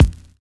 FINE BD    2.wav